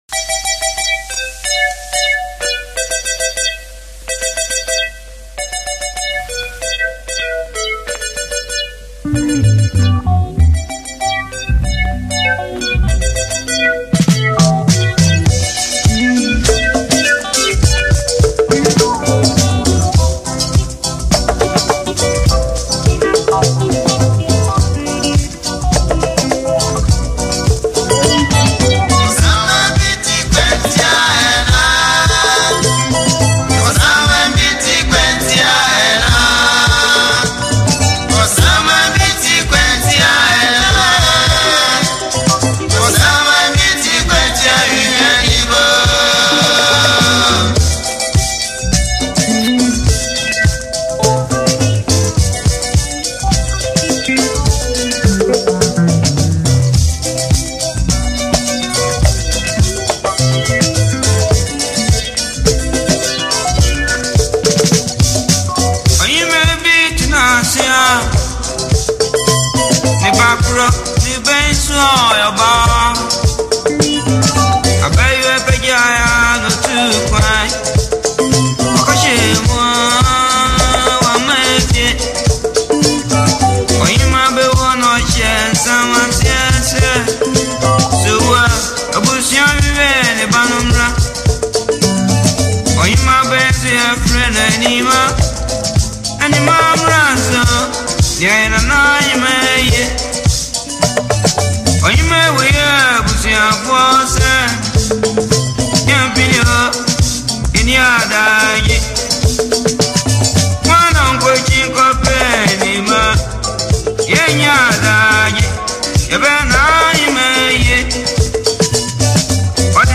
an old classical song